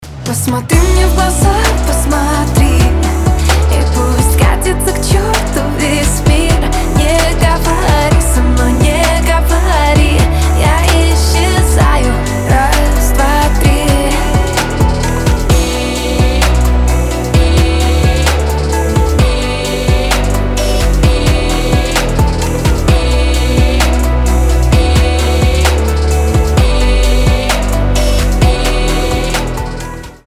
• Качество: 320, Stereo
поп
спокойные